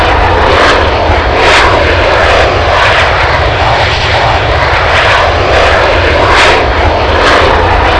xrumble_side.wav